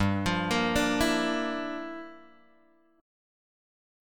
G Minor 13th